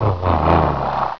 Kuh), die vom Bullen mit einem hustenden Bellen (
Die Brunftrufe sind noch bis in 3 km Entfernung zu hören.
bulle.wav